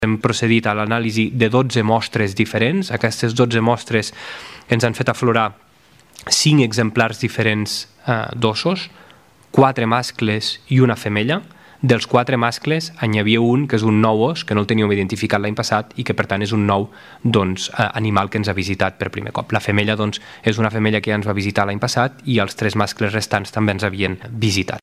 El ministre portaveu, Casal, n’ha donat més detalls.